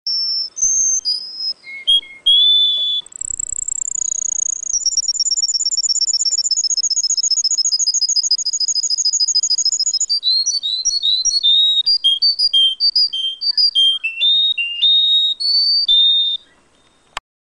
Классическая песня русской канарейки овсяночного напева